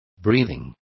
Complete with pronunciation of the translation of breathing.